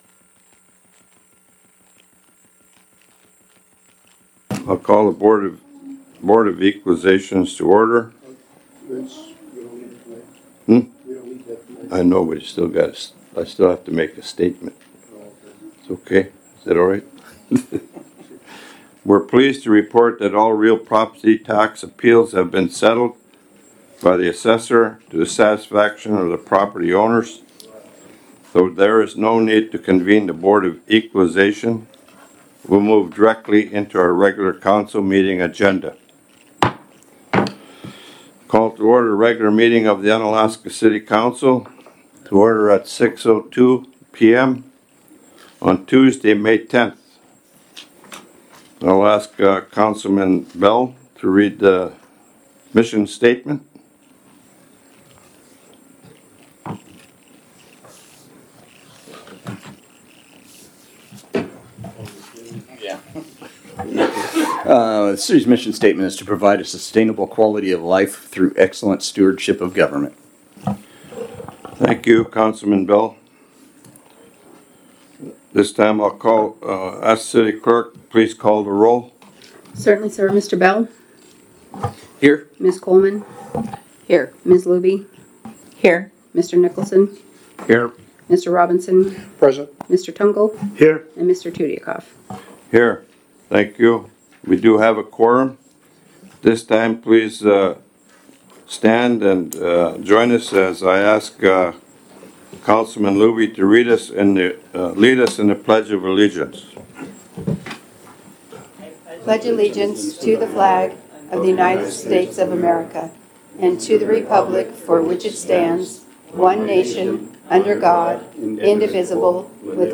Council Meeting - May 10, 2022 | City of Unalaska - International Port of Dutch Harbor
In person at City Hall (43 Raven Way)